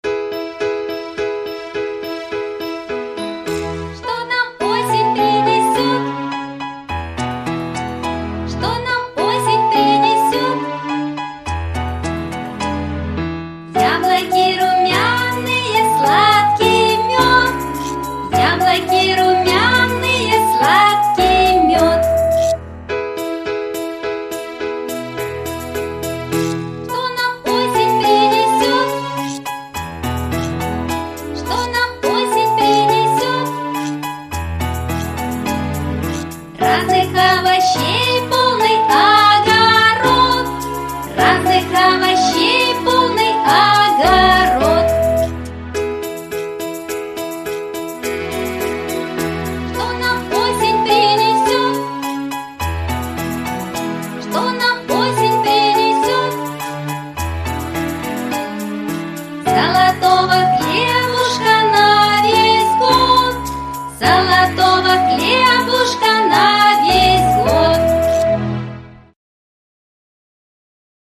Песенки про осень